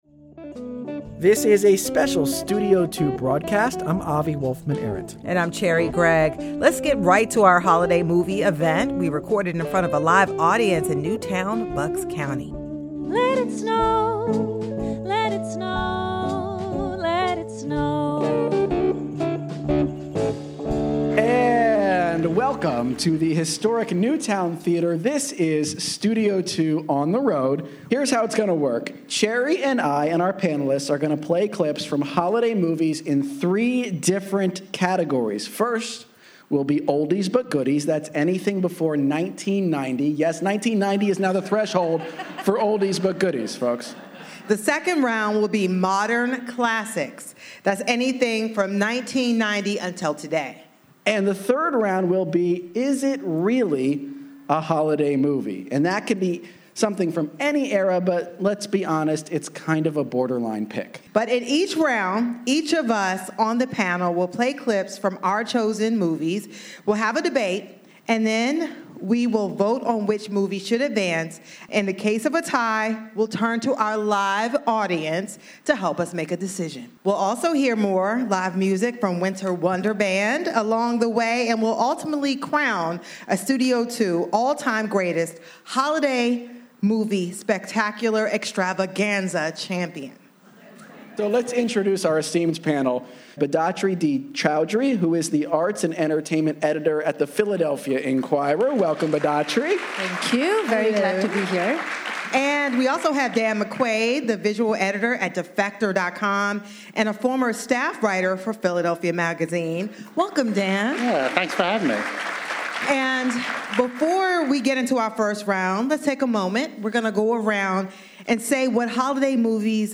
On the first day of spring, we invite two master gardeners to answer your questions about planting, growing, weeding, mulching and more.